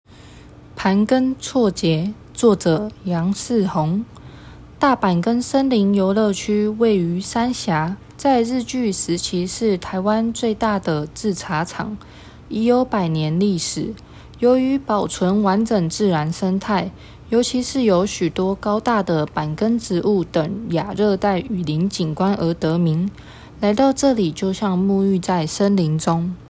語音導覽，另開新視窗